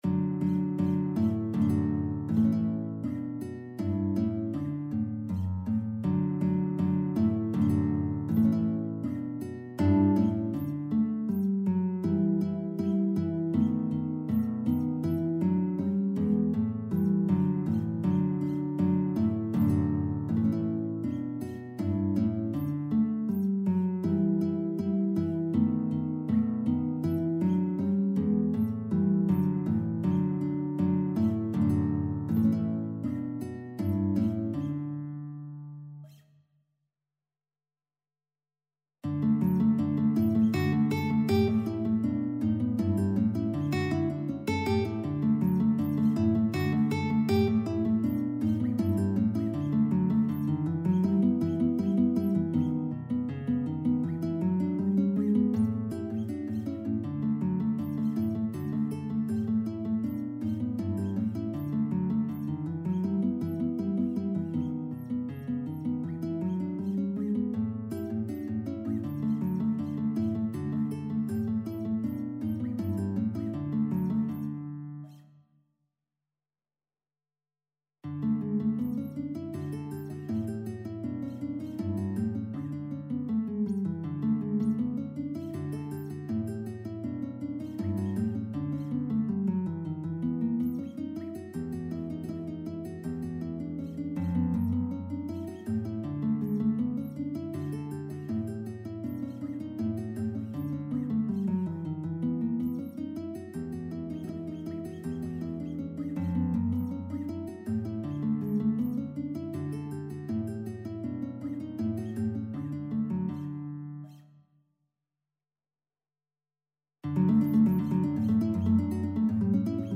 Classical Carcassi, Matteo Au Clair de la Lune, chanté dans 'Les Voitures Versées', varié, Op.7 Guitar version
Guitar  (View more Intermediate Guitar Music)
Classical (View more Classical Guitar Music)